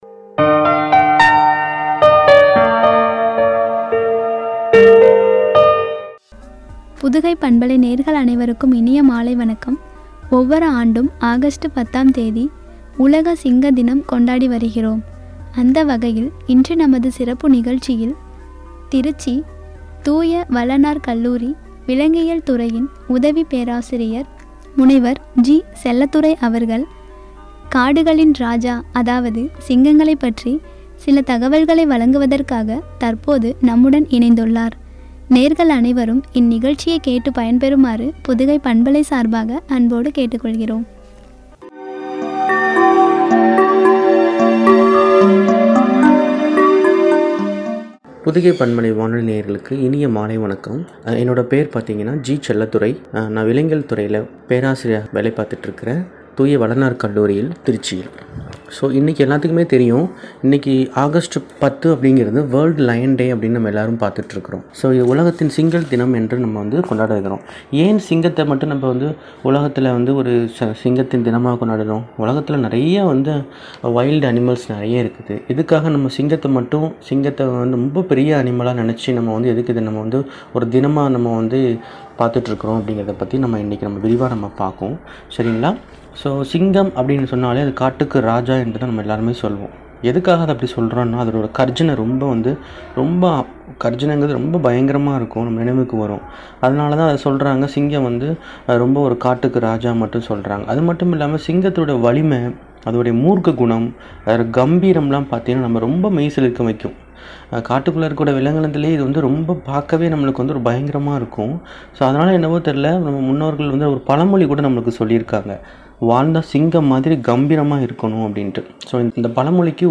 வழங்கிய உரையாடல்